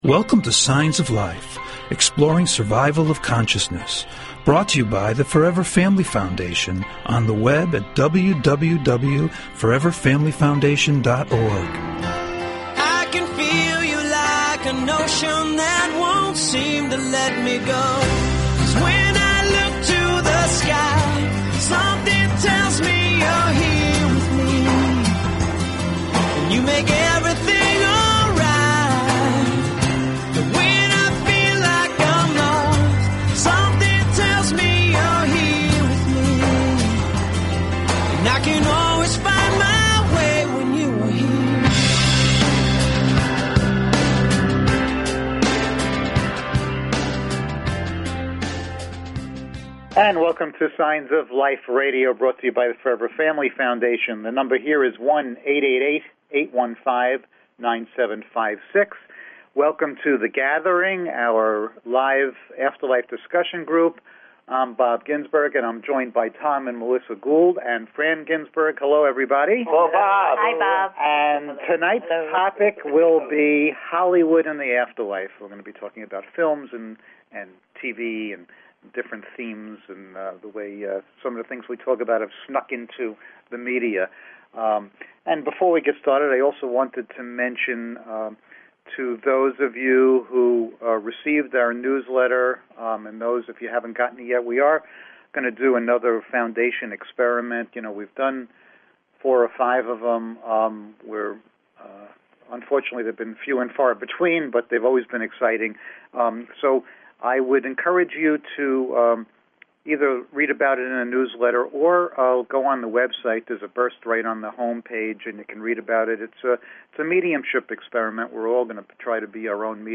SHORT DESCRIPTION - The Gathering - A live afterlife discussion show - Topic: Hollywood and the Afterlife
Call In or just listen to top Scientists, Mediums, and Researchers discuss their personal work in the field and answer your most perplexing questions.